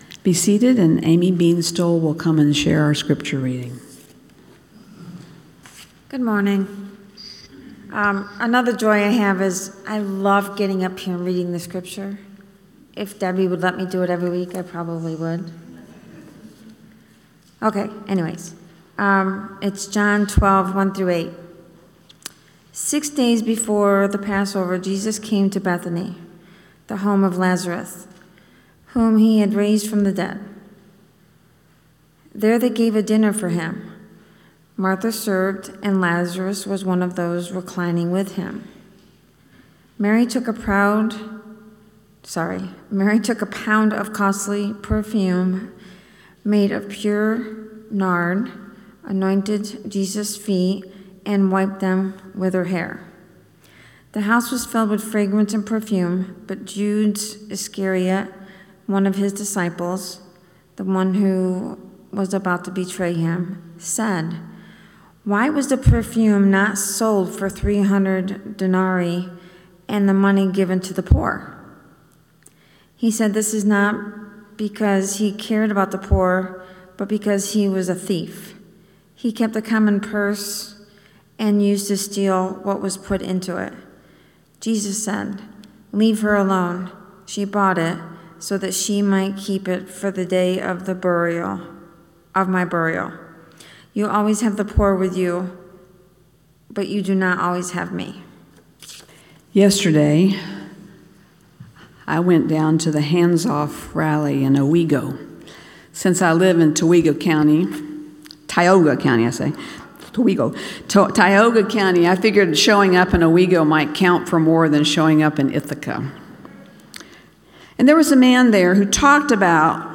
Recorded Sermons - The First Baptist Church In Ithaca